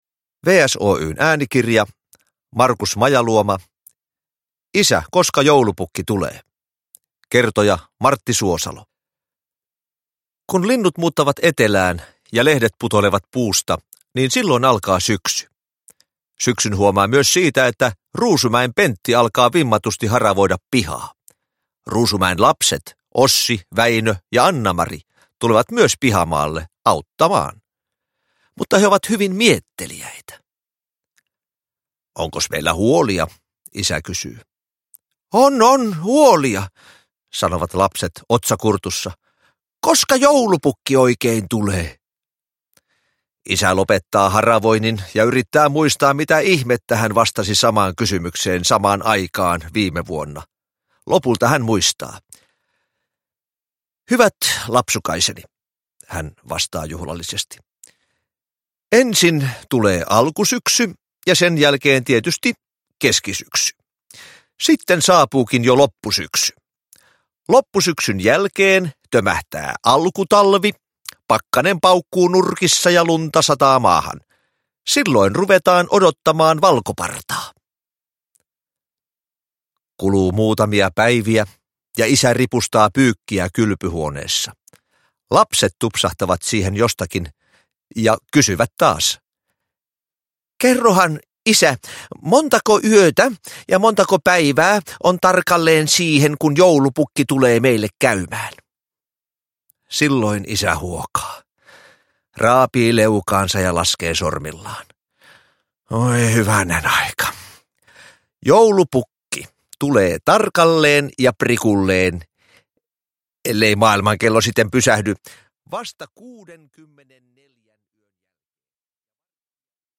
Uppläsare: Martti Suosalo